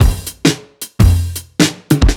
OTG_Kit 5_HeavySwing_110-C.wav